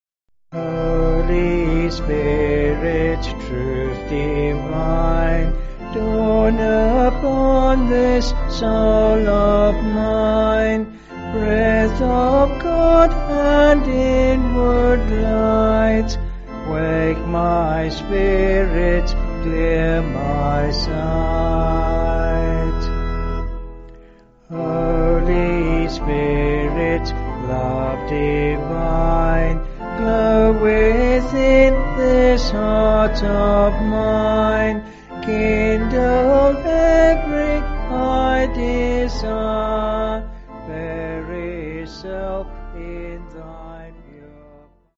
Vocals and Organ